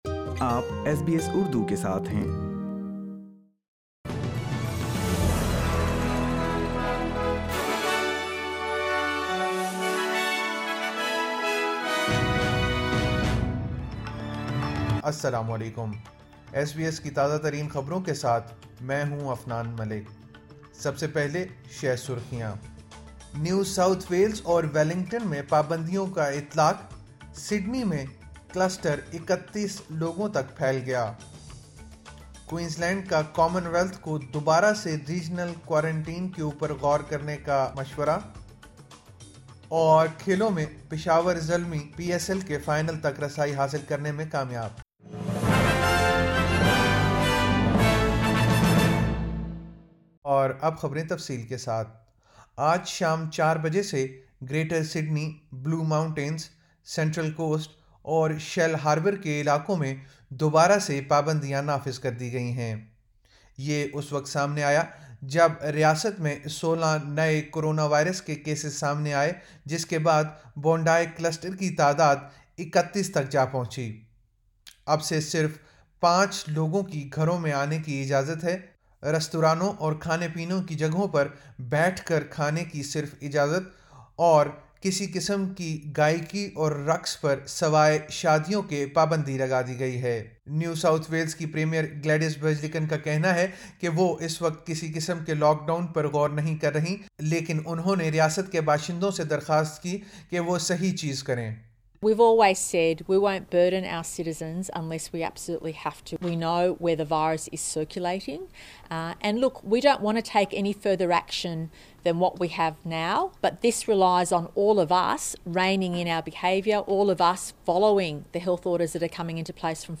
SBS Urdu News 23 June 2021